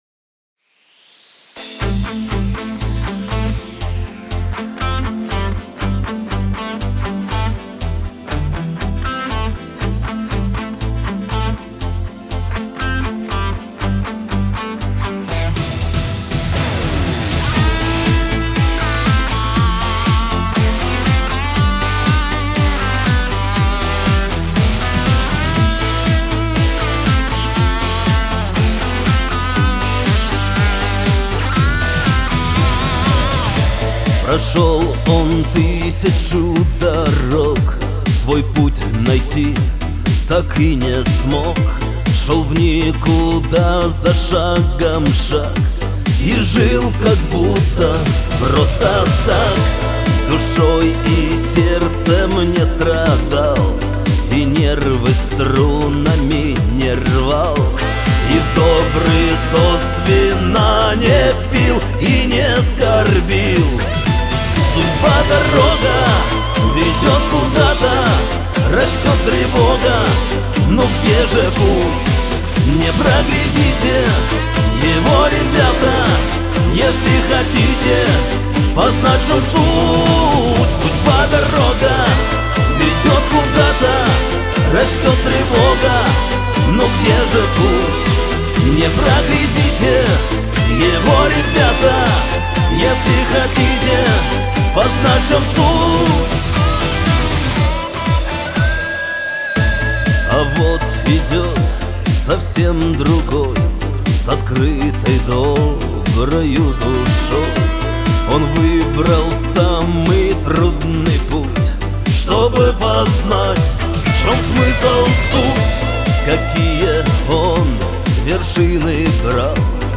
эстрада
dance
pop , диско